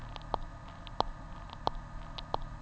The present paper analyzes the sounds emitted by pre-hatching chicks, focusing on those named as “clicks,” which are thought to mediate pre-hatching social interactions and hatching synchronization.
As hatching approaches, clicks evolve from isolated events to highly organized hierarchical clusters.